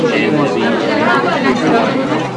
Crowded Sound Effect
crowded.mp3